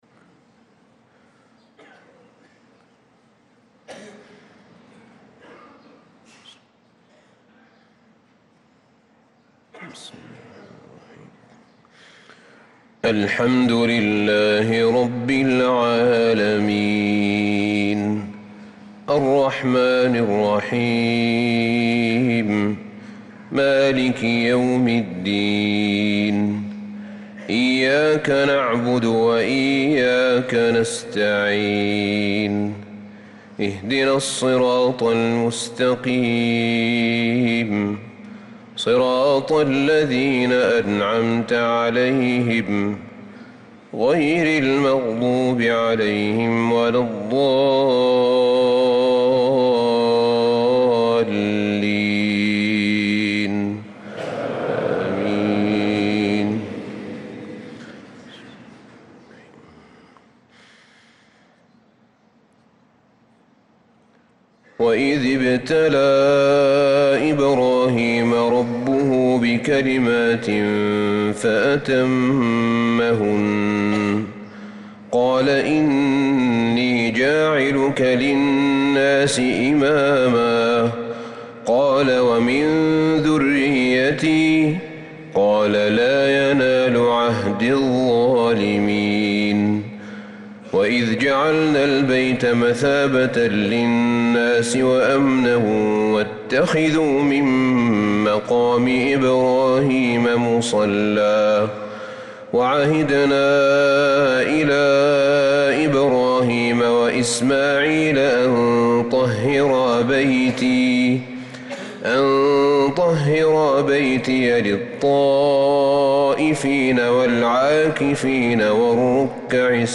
تِلَاوَات الْحَرَمَيْن .